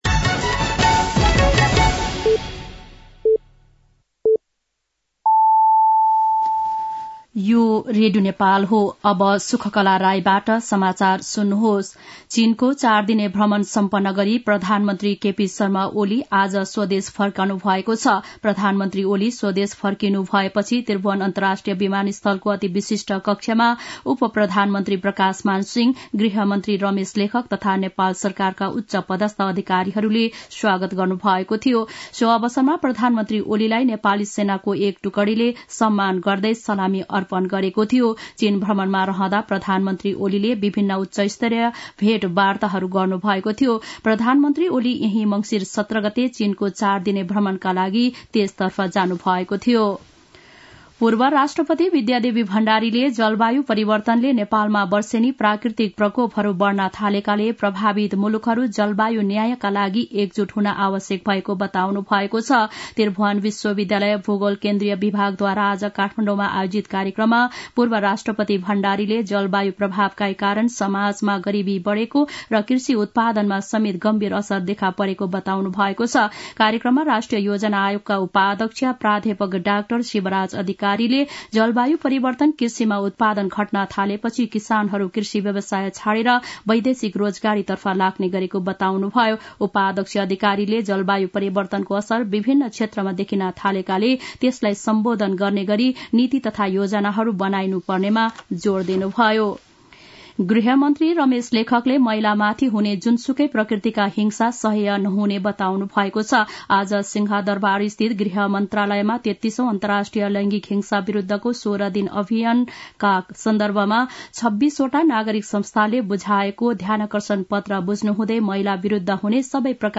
दिउँसो ४ बजेको नेपाली समाचार : २१ मंसिर , २०८१
4-pm-nepali-news-1-2.mp3